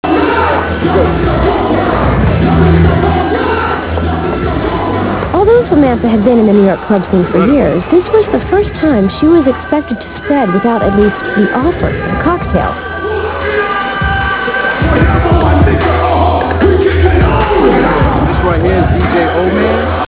Comment: hip-hop